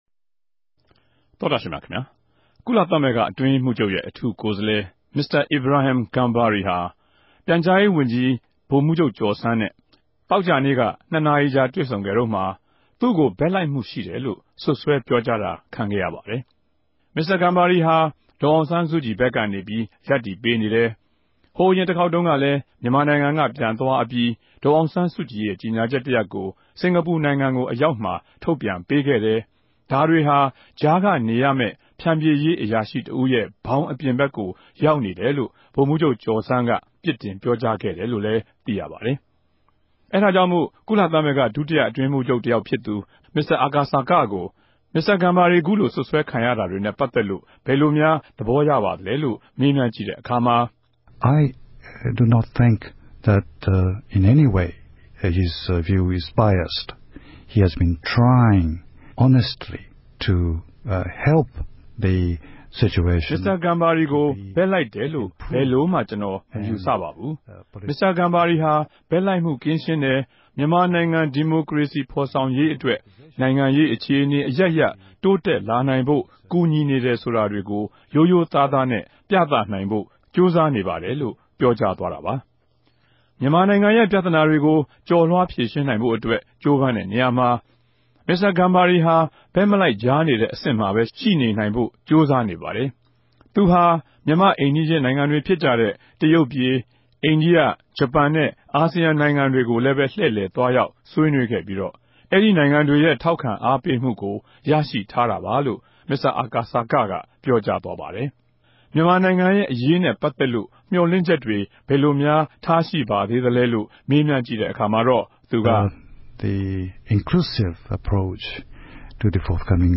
ကုလသမဂ္ဂ ဒုတိယ အတြင်းရေးမြြးခဵြပ် မင်္စတာ အာကာ စာက ကို အာအက်ဖ်အေ စတူဒီယိုတြင် တြေႚဆုံမေးူမန်းစဉ်၊ (Photo: RFA) ပုံဋ္ဌကီးုကည့်လိုလ္တွင်။ >>